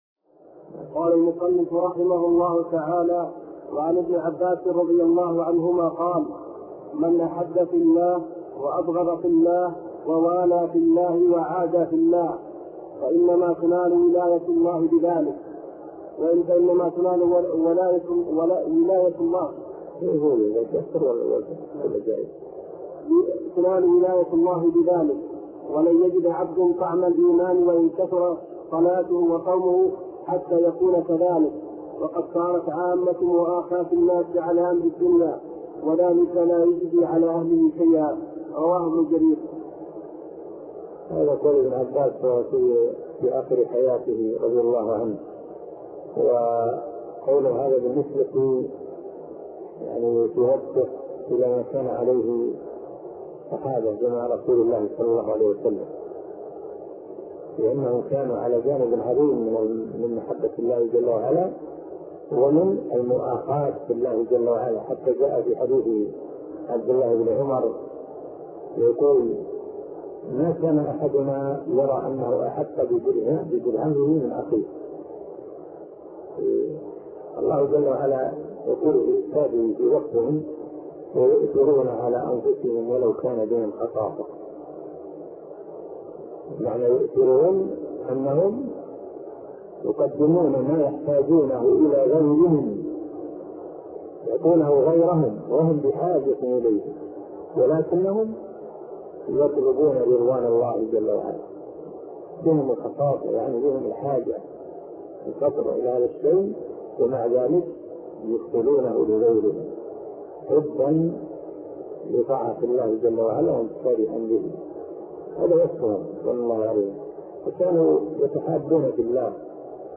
عنوان المادة الدرس ( 87) شرح فتح المجيد شرح كتاب التوحيد تاريخ التحميل الجمعة 16 ديسمبر 2022 مـ حجم المادة 28.31 ميجا بايت عدد الزيارات 219 زيارة عدد مرات الحفظ 113 مرة إستماع المادة حفظ المادة اضف تعليقك أرسل لصديق